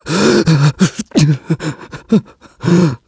voices / heroes / en
Clause_ice-Vox_Sad.wav